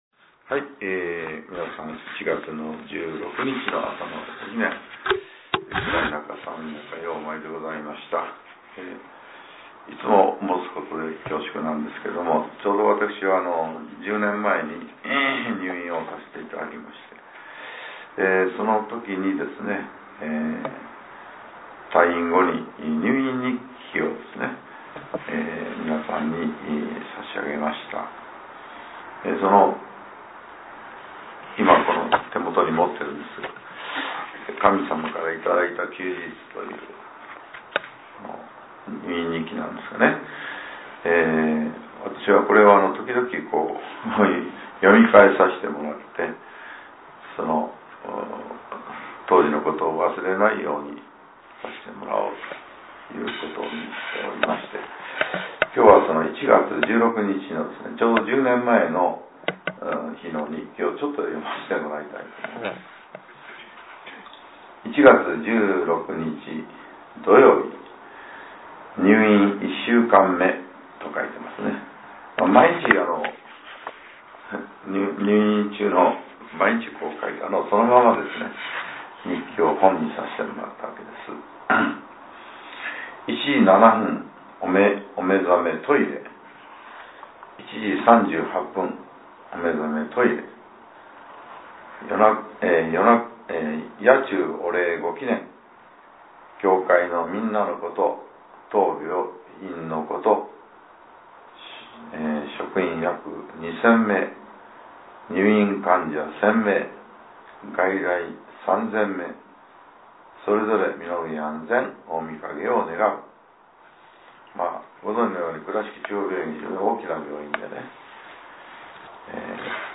令和８年１月１６日（朝）のお話が、音声ブログとして更新させれています。 きょうは、前教会長による「入院日記を振り返る」です。